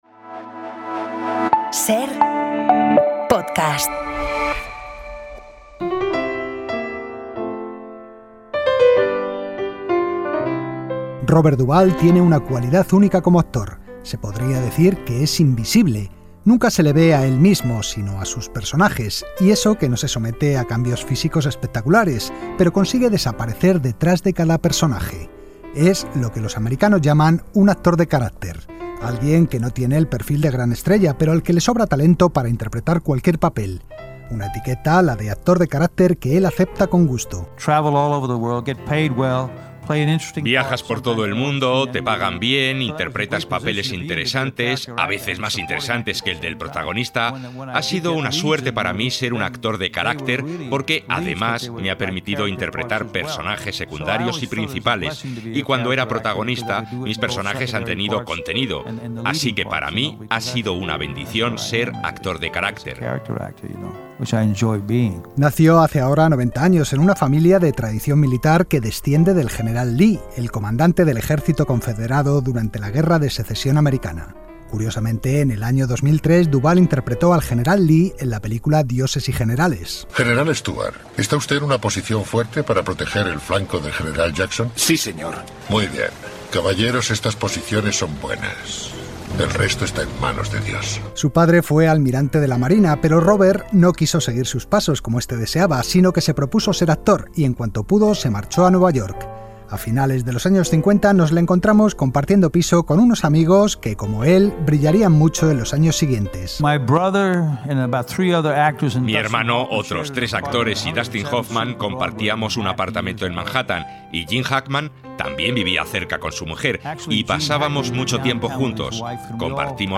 Robert Duvall falleció el pasado domingo a los 95 años de edad en su rancho de Virginia donde vivía junto a su esposa, la actriz argentina Luciana Pedraza. En su recuerdo hemos recuperado un reportaje que emitimos en el programa en 2021, cuando el actor cumplió 90 años.